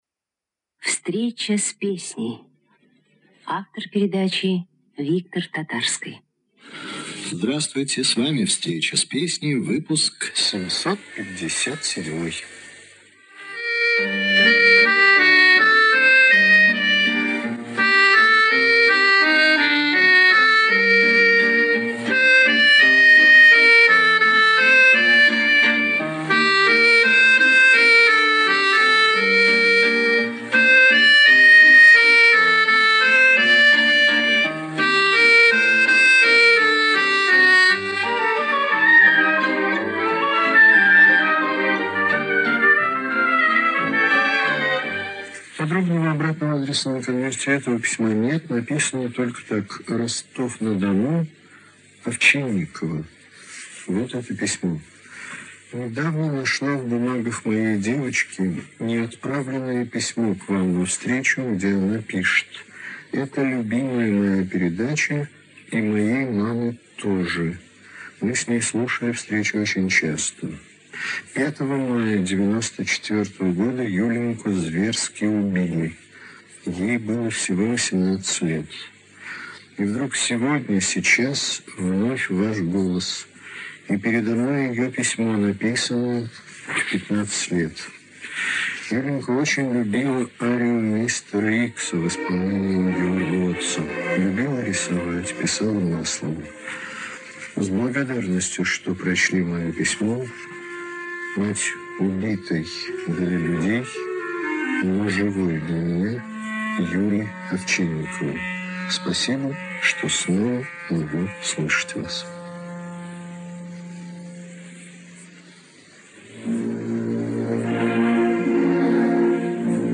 Запись потока с сайта "Ретропортал". Звук совсем плохой, не удалось улучшить.